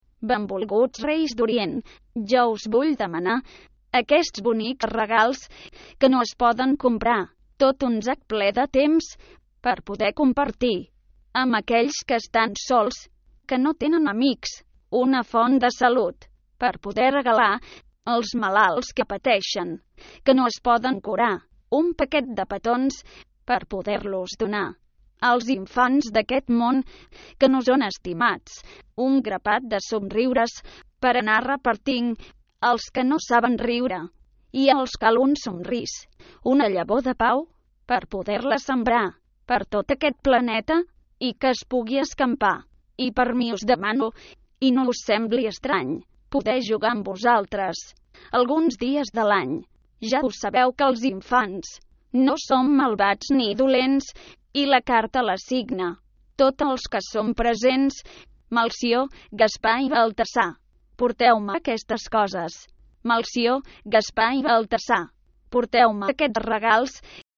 Si cliqueu en el signe + podreu escoltar com el llegeix una veu sintetitzada.